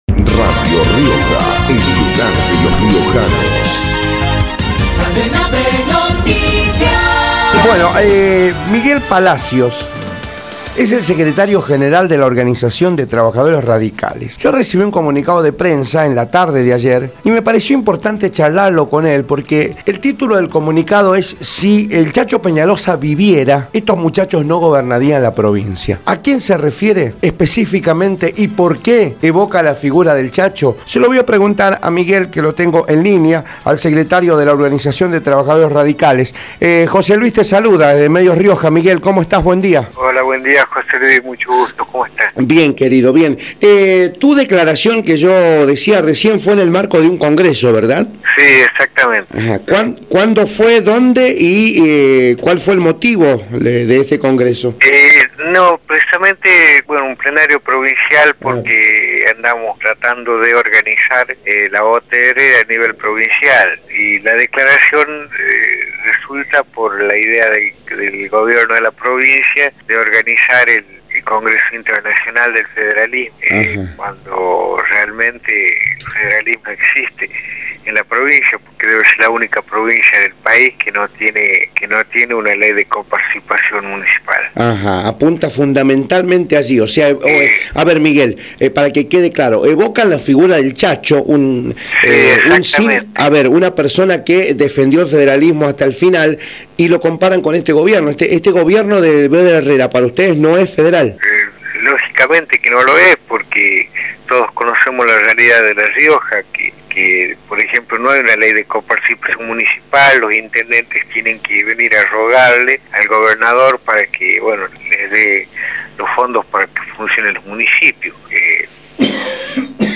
por Radio Rioja